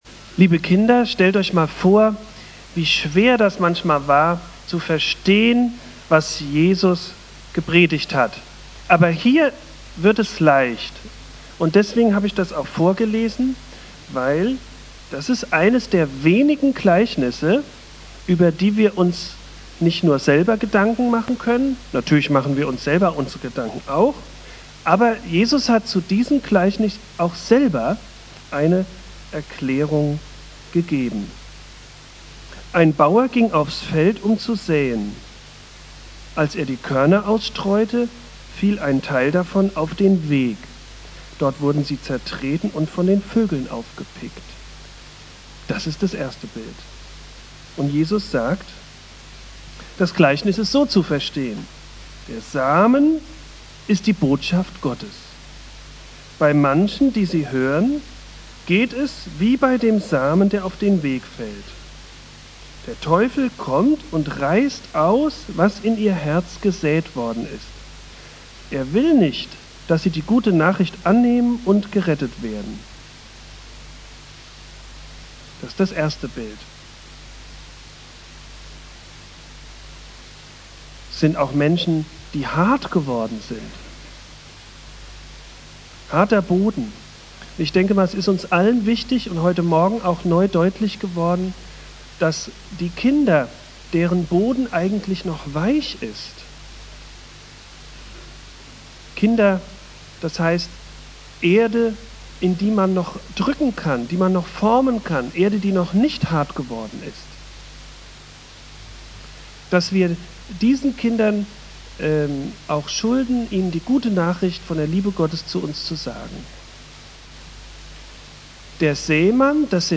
"Der vierfache Acker" (Familiengottesdienst - Erntedank) Bibeltext